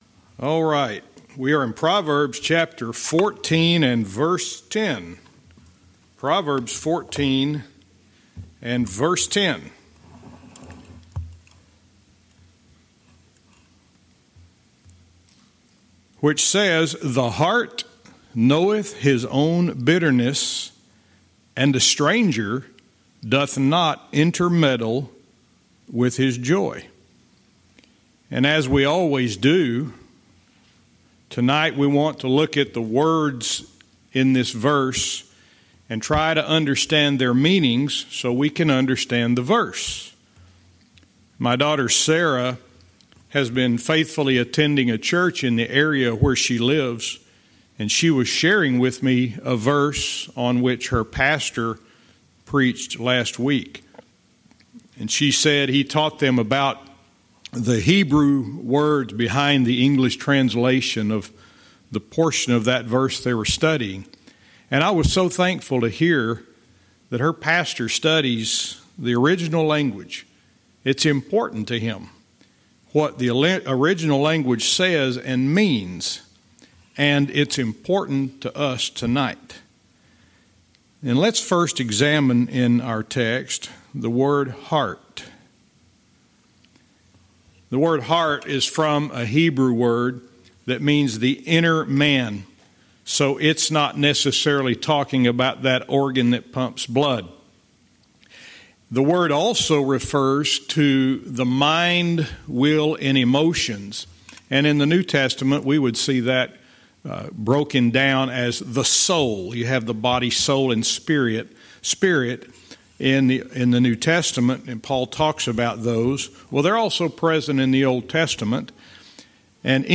Verse by verse teaching - Proverbs 14:10